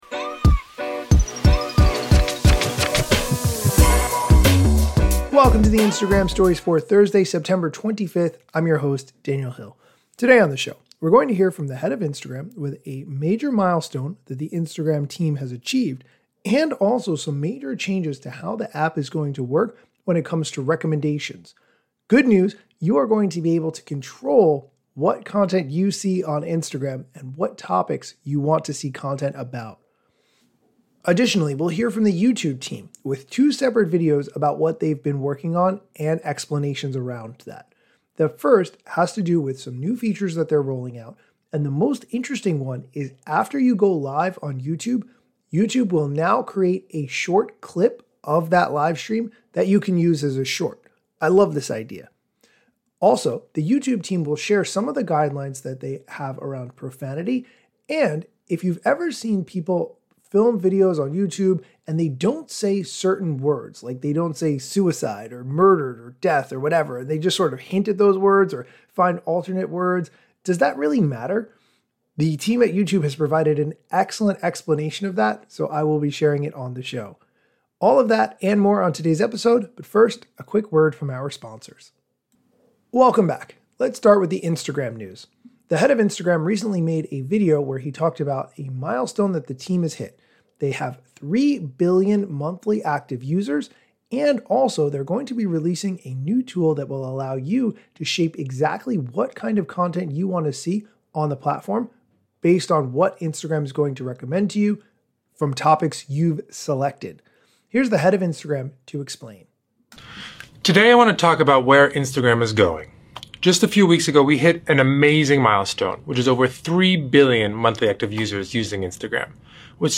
Today's episode features a clip from the Head of Instagram, Adam Mosseri, about how they've hit 3 billion monthly active users, and explaining how they're rolling out controls to allow you to determine what topics you see content about.